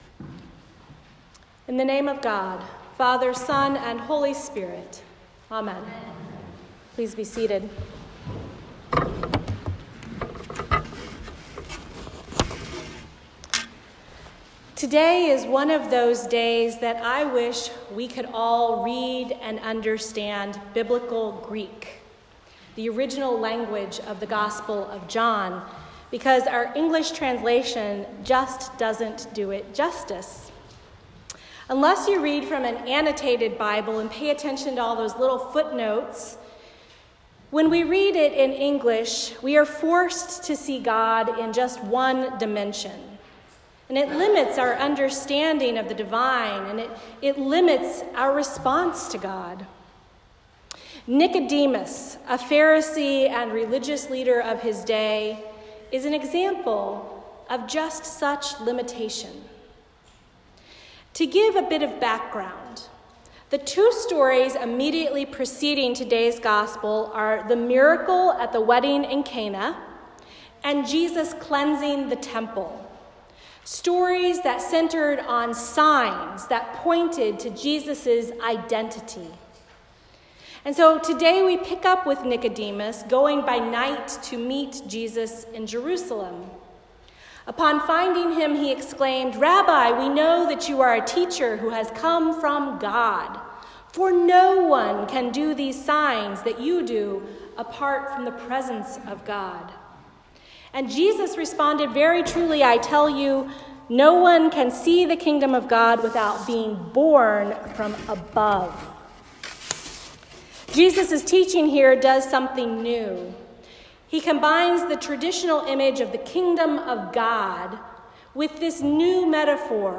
A Sermon for the Second Sunday in Lent